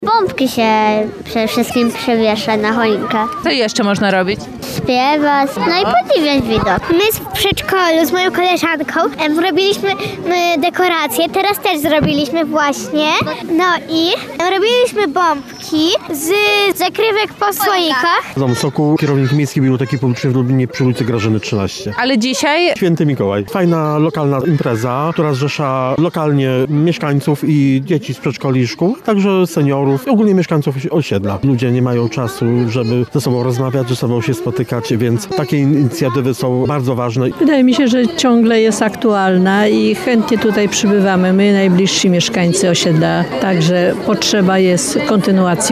Jak co roku, mieszkańcy udekorowali na święta drzewko rosnące na jednym z placów w centrum osiedla. Uroczystości towarzyszył kiermasz słodkości oraz wspólne kolędowanie.